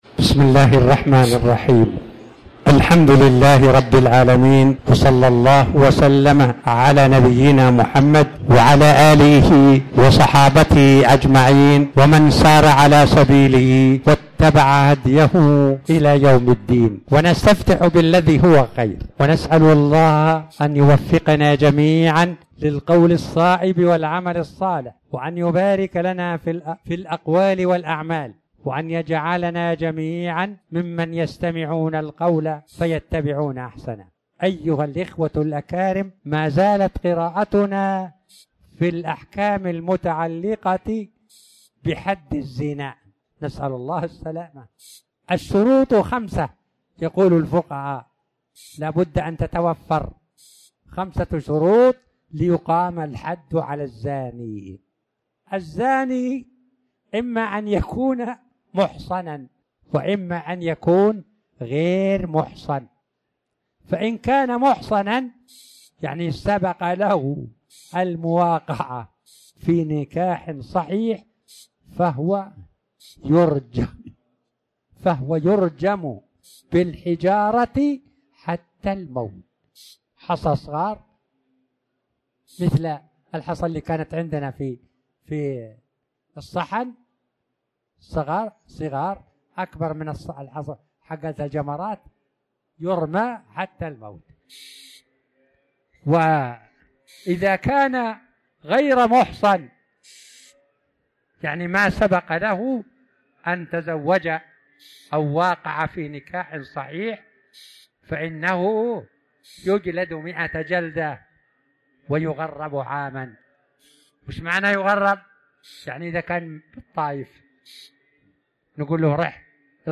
تاريخ النشر ١٨ ربيع الأول ١٤٤٠ هـ المكان: المسجد الحرام الشيخ